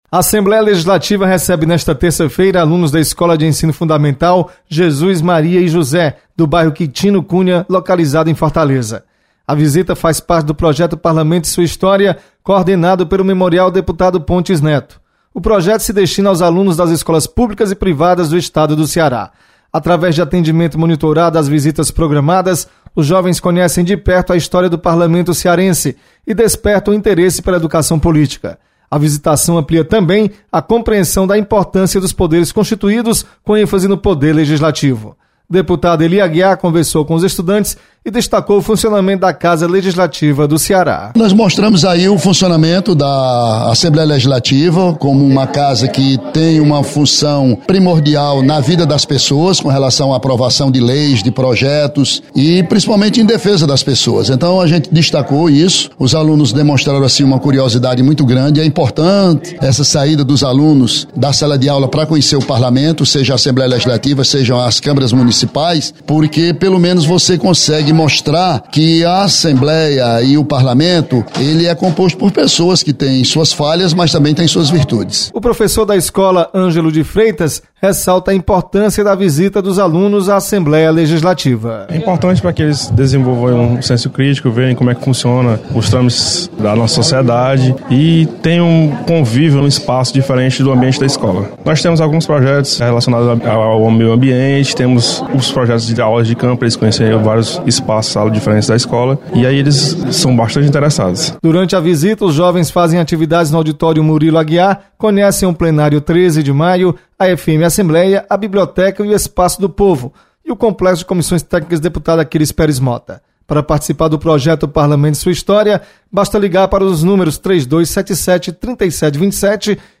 Você está aqui: Início Comunicação Rádio FM Assembleia Notícias Memorial